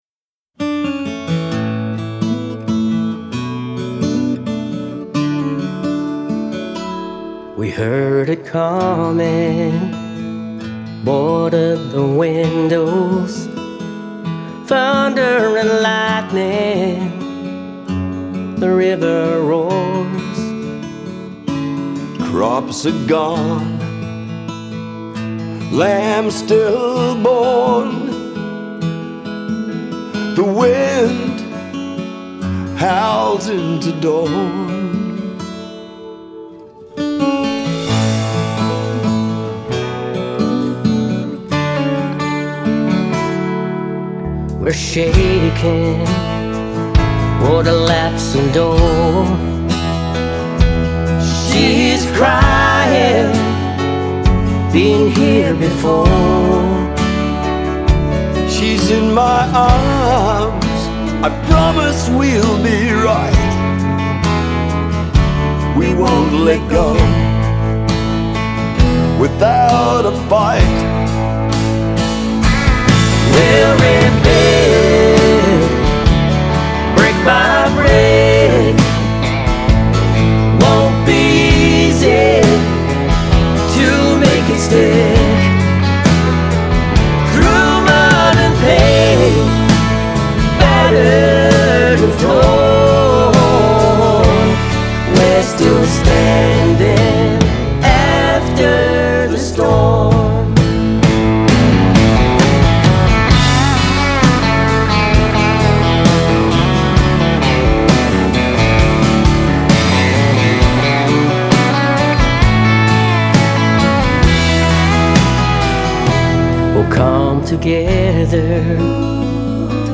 Celebrated Australian country music legend
the dynamic country duo